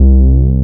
17BASS01  -R.wav